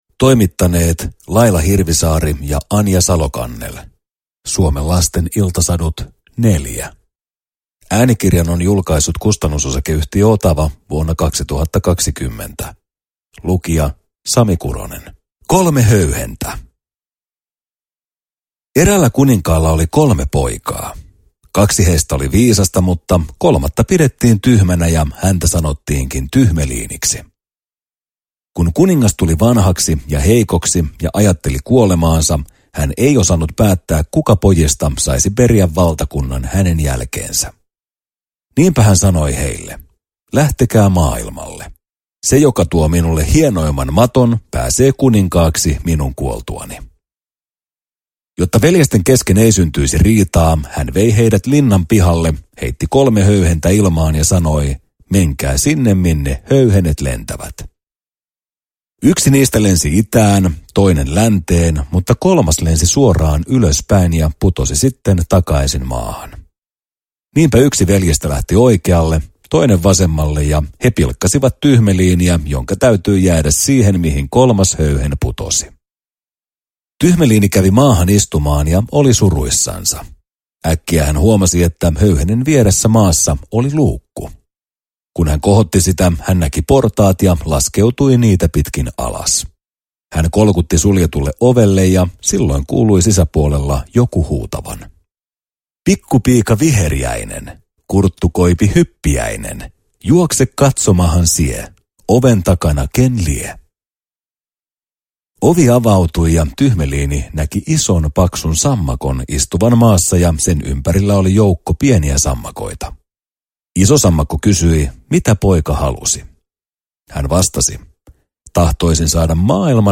Suomen lasten iltasadut 4 – Ljudbok – Laddas ner